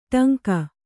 ♪ ṭaŋka